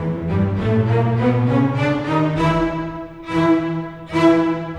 Rock-Pop 20 Bass, Cello _ Viola 01.wav